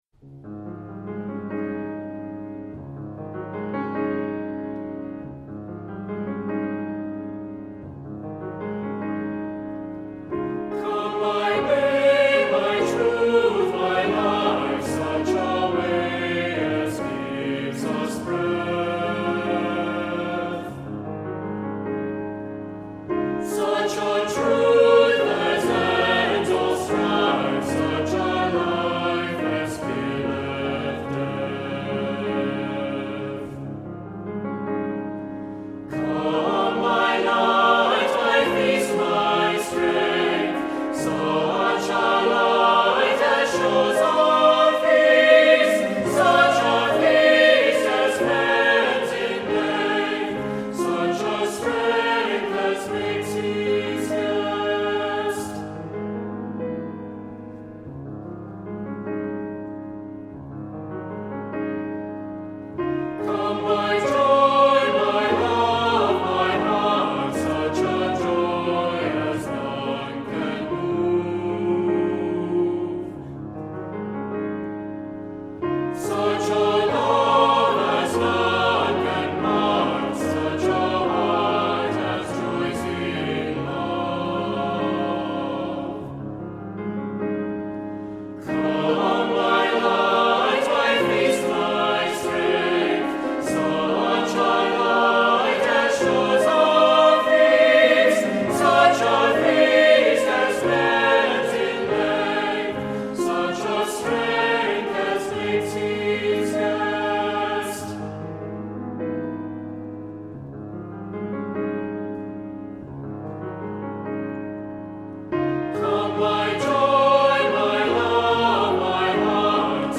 Voicing: Two-part equal; Two-part mixed